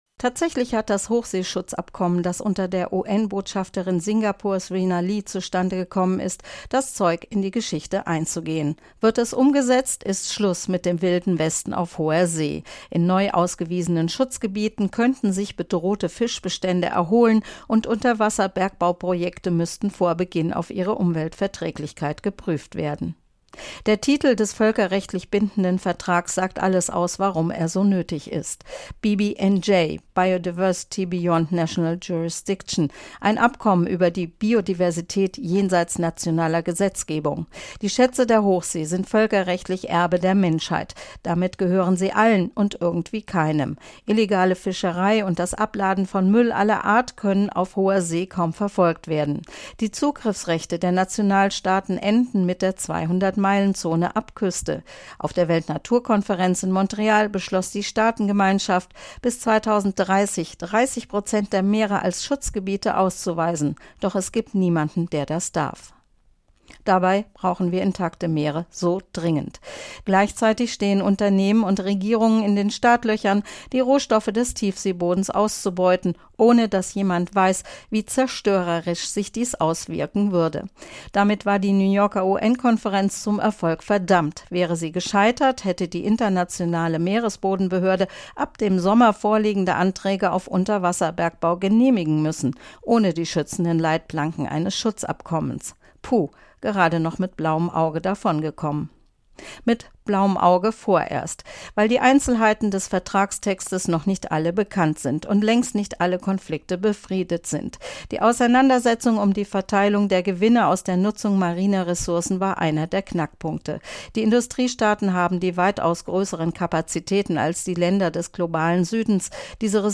Kommentar UN-Hochseeschutzabkommen verabschiedet